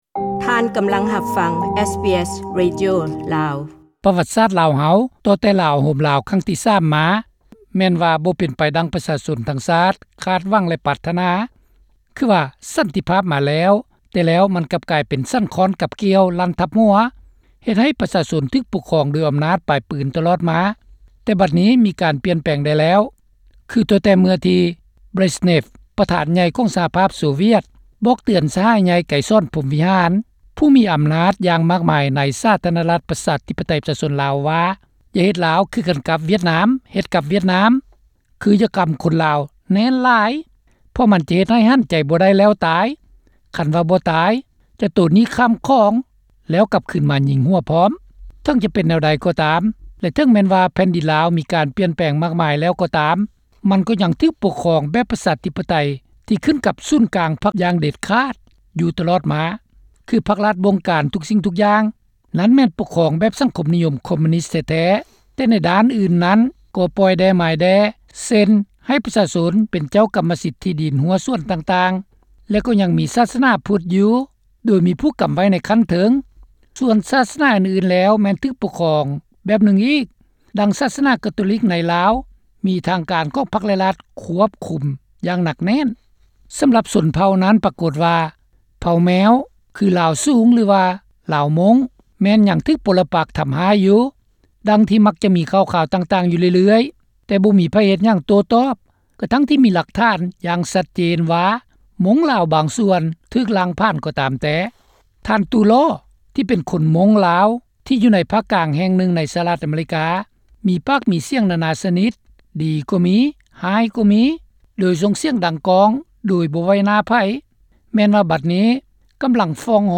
ສຳພາດ: